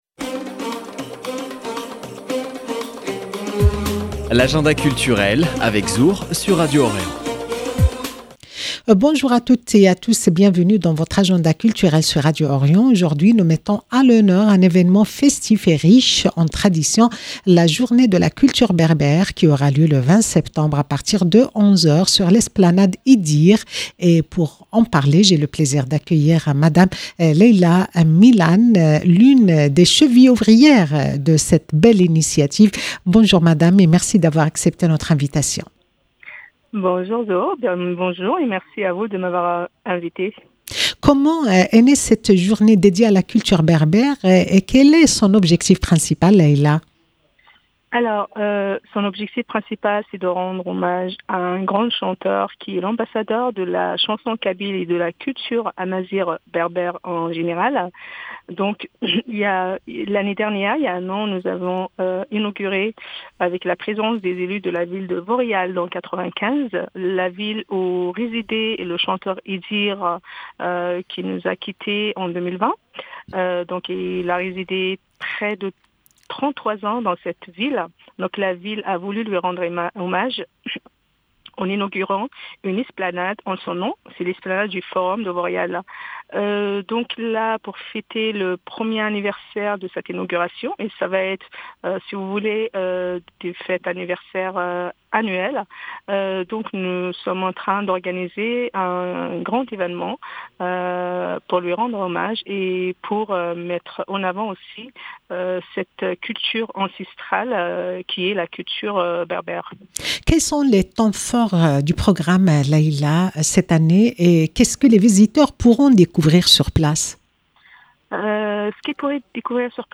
Agenda culturel